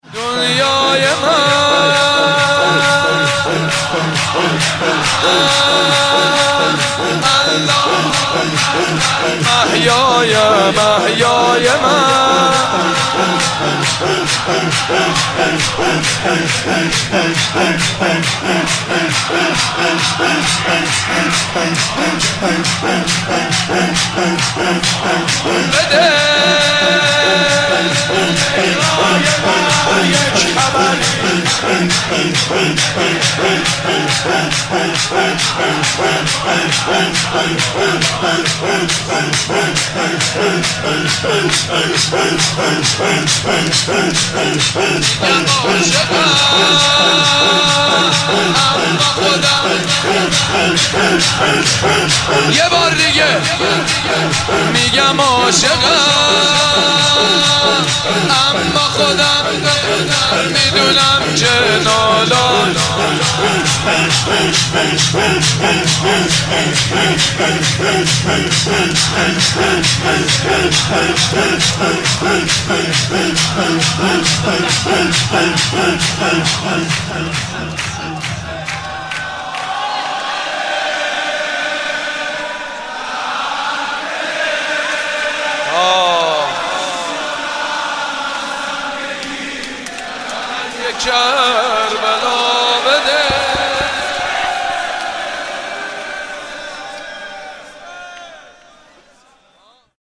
شور: دنیای من، آقای من